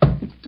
PixelPerfectionCE/assets/minecraft/sounds/mob/horse/wood3.ogg at mc116